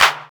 Index of /90_sSampleCDs/USB Soundscan vol.01 - Hard & Loud Techno [AKAI] 1CD/Partition D/16-SOFT KIT
CLAP     3-R.wav